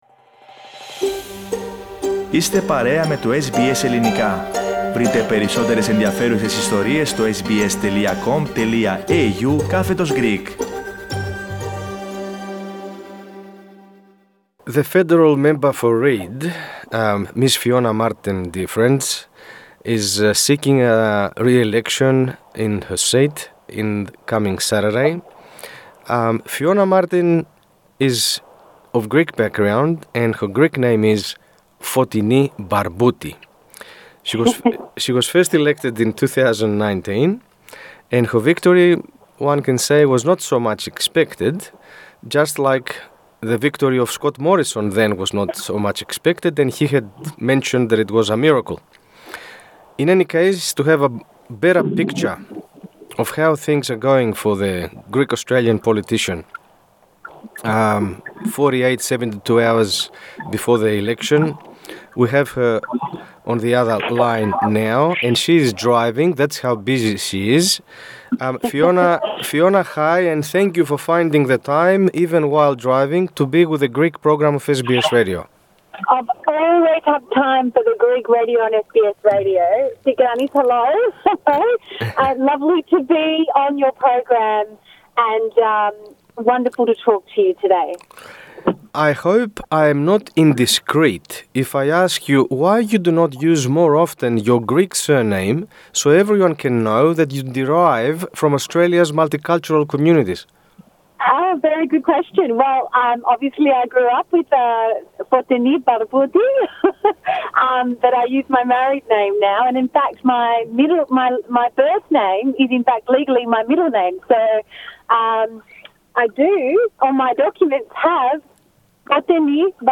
In the interview she gave to SBS Greek she elaborates on her connection with the multicultural communities, the reasons she decided to get actively into politics, and her experiences so far. She also talks about instances where she had to go against the recommendation of her party on voting bills and how she overcame this challenge.